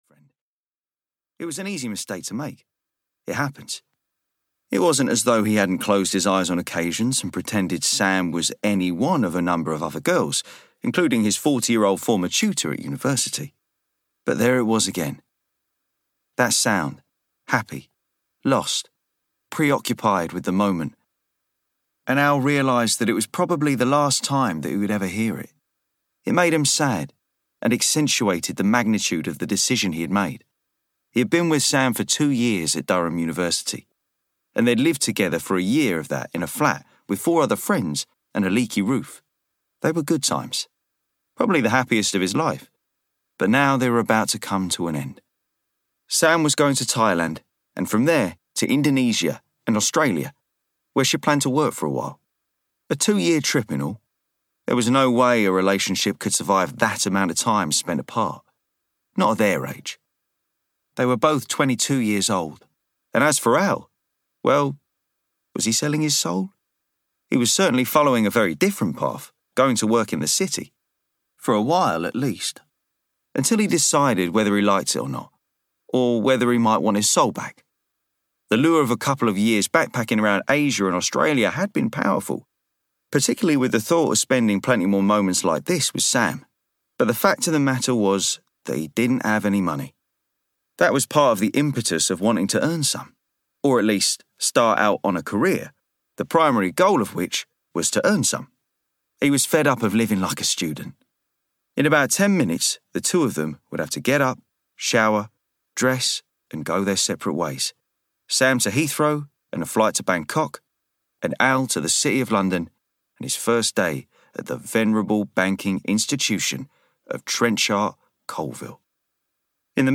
Shadow Banking (EN) audiokniha
Ukázka z knihy